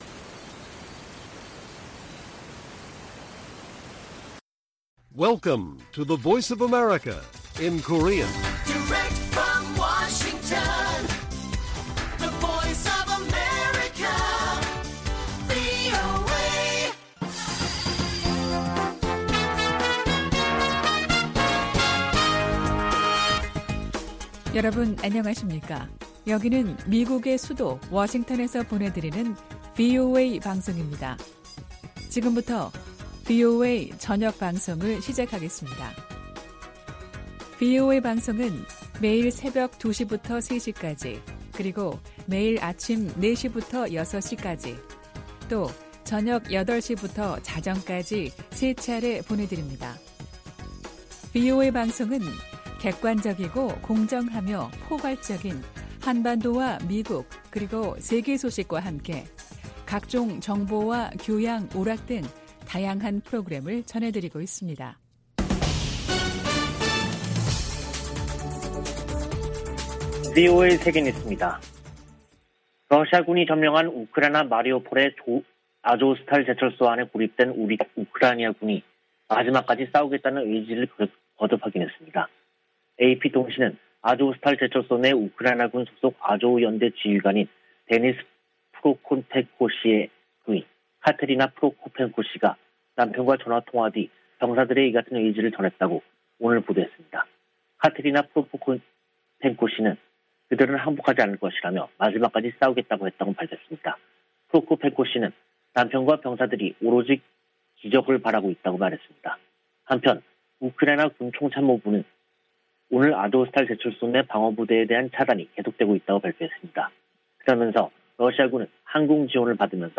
VOA 한국어 간판 뉴스 프로그램 '뉴스 투데이', 2022년 5월 6일 1부 방송입니다. 백악관은 조 바이든 대통령의 한일 순방에서 ‘확장억지’ 약속과, 북한 문제가 중점 논의 될 것이라고 밝혔습니다. 미 국무부는 북한의 거듭되는 미사일 발사에 대응이 따를 것이라는 분명한 신호를 보내야 한다고 강조했습니다. 미 상원이 필립 골드버그 주한 미국대사 인준안을 가결했습니다.